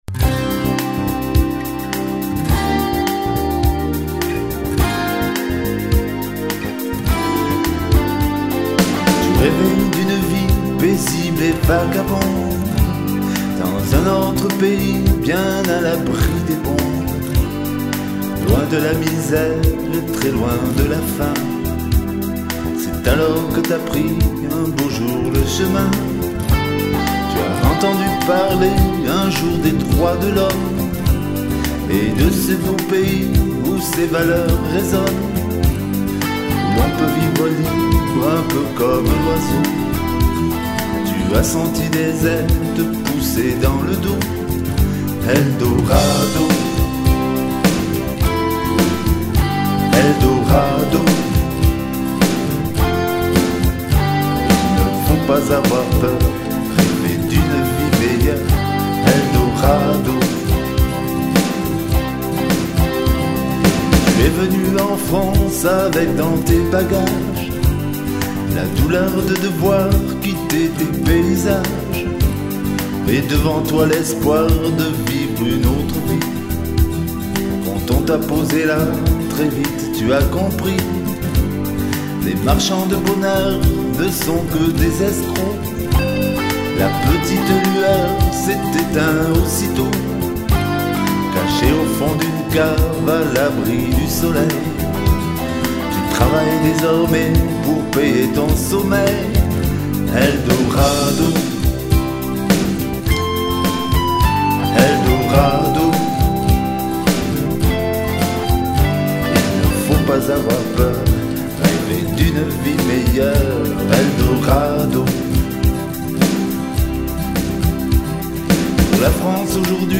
Leur naissance étant répartie sur une période de 30 ans, la qualité d'enregistrement n'est pas égale.